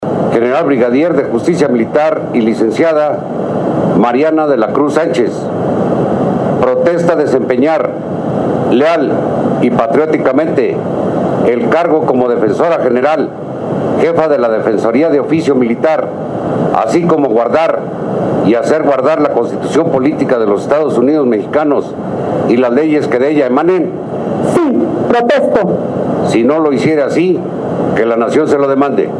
– El Subsecretario de la Defensa Nacional Enrique Covarrubias López tomó protesta y dio posesión al cargo de Defensora General y Jefa de la Defensoría de Oficio Militar a la General Mariana de la Cruz Sánchez a quien exhortó a cumplir con su deber.
JURAMENTO-GENERALA-7-ENERO.mp3